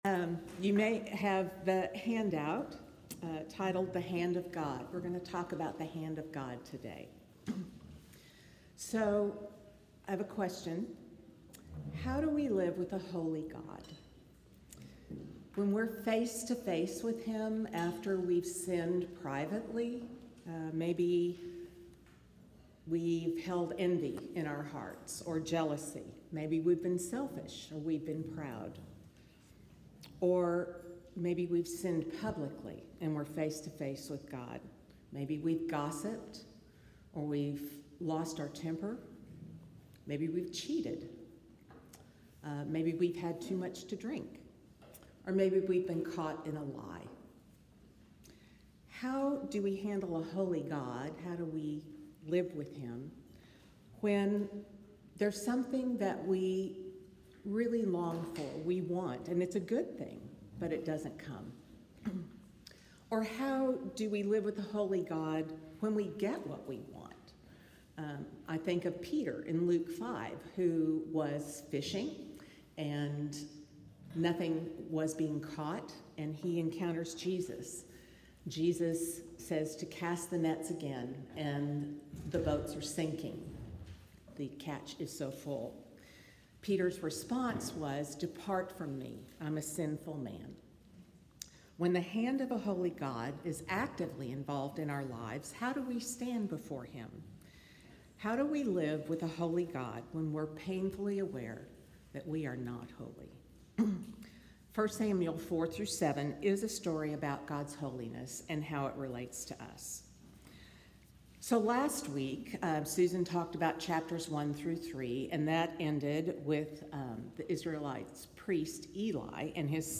Service Type: Lectures